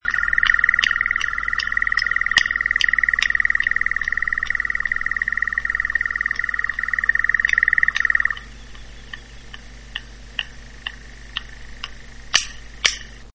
klaskanie.mp3